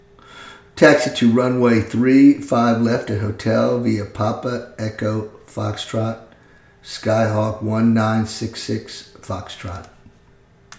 Aviation Radio Calls
07a_PilotRunwayThreeFiveLeftAtHotel.mp3